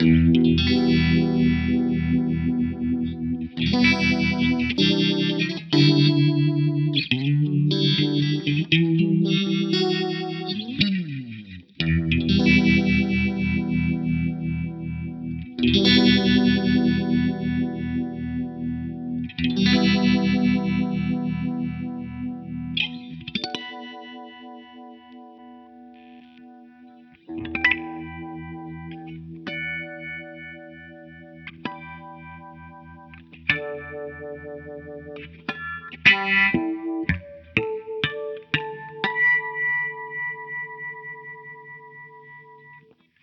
L'Univibe Dunlop a effectivement un mode vibrato ; cependant, son mode principal se rapproche plus du Phaser que du Chorus. A la base l'effet était sensé reproduire l'effet des cabines Leslies, mais avec au final un résultat complètement différent.
Un sample d'Univibe :